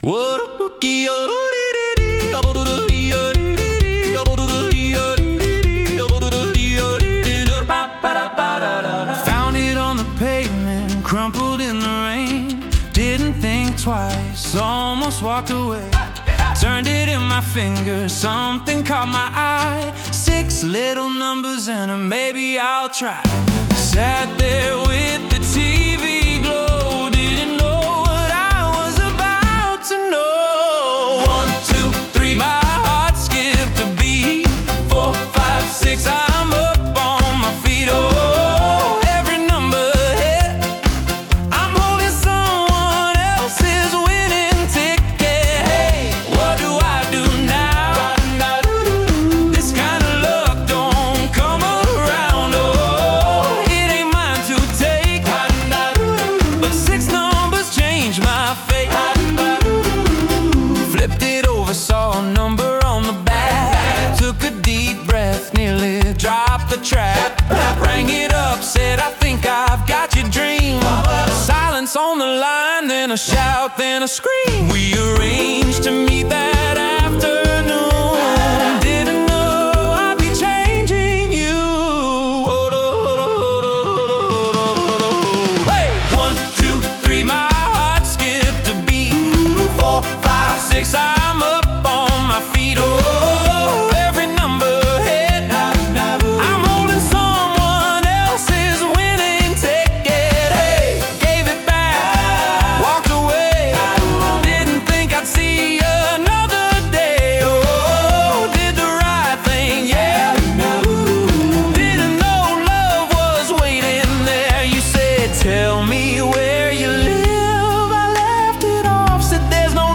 Yodelling